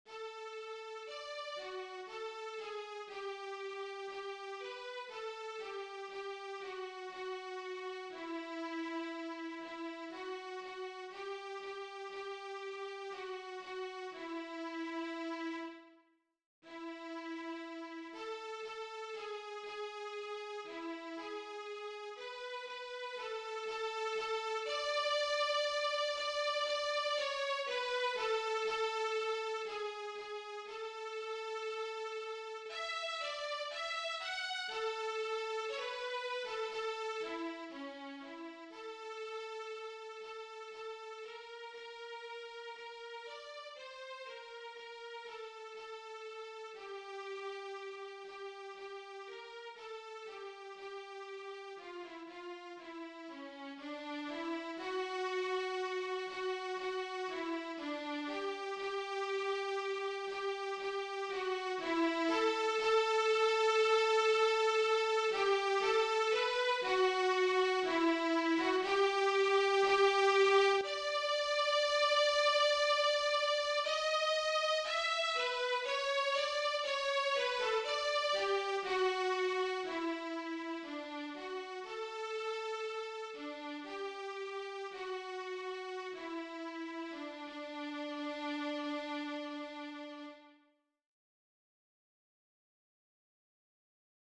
DIGITAL SHEET MUSIC - VIOLIN SOLO
Violin Solo